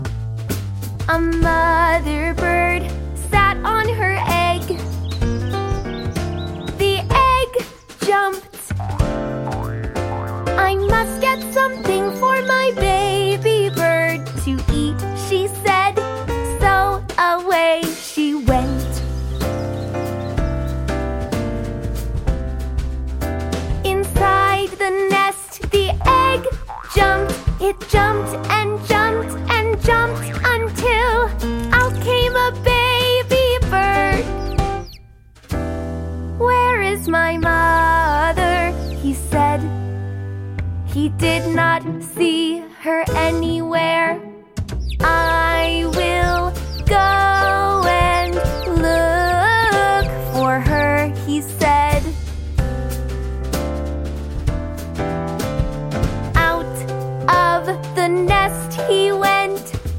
リズミカルな繰り返しが多くて、小さいお子さんにピッタリ。
3. Story Reading
6. Song with Children